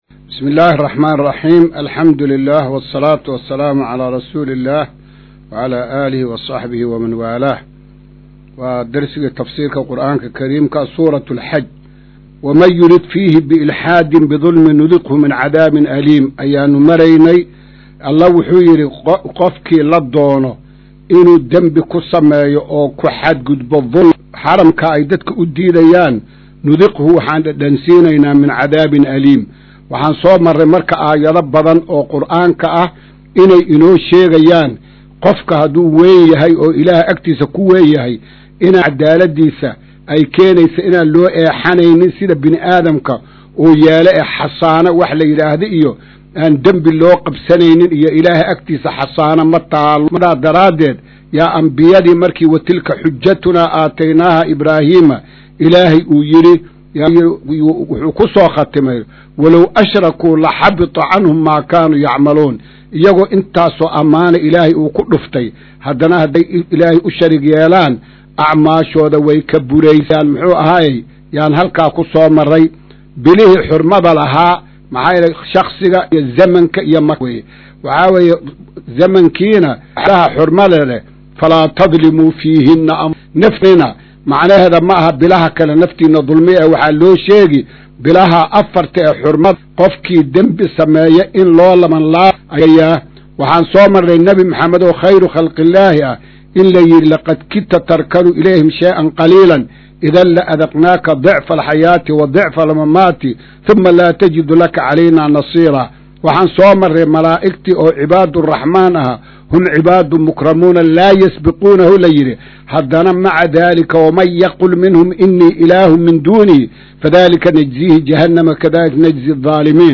Maqal:- Casharka Tafsiirka Qur’aanka Idaacadda Himilo “Darsiga 162aad”